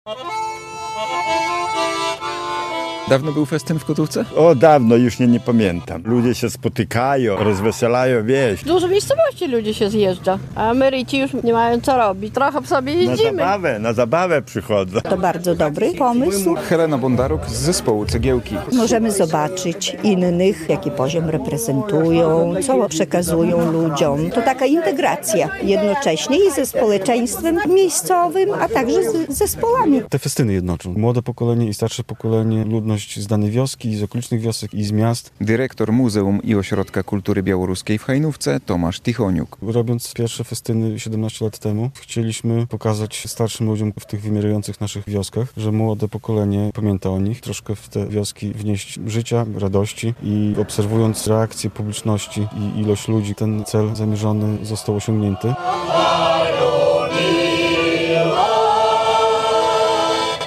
We wsi Kotówka pod Hajnówką trwa właśnie jubileuszowy, setny koncert festynu "I tam żyją ludzie".